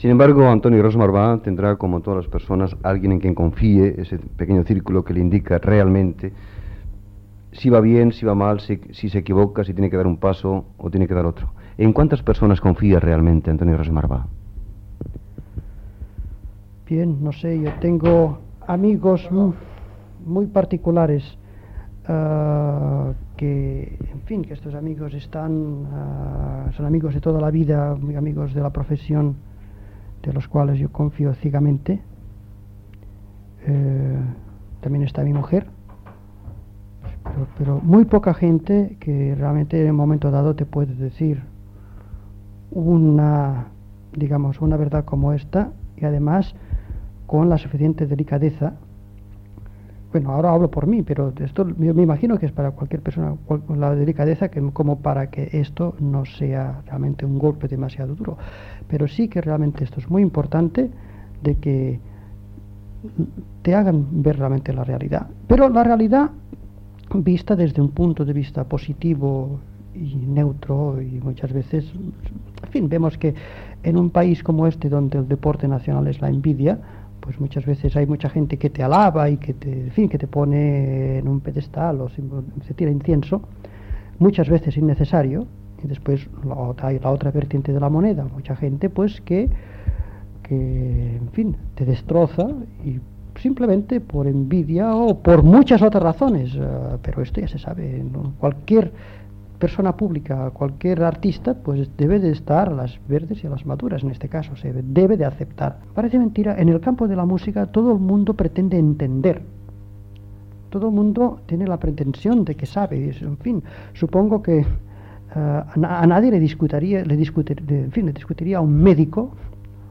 Entrevista al director d'orquestra Antoni Ros Marbà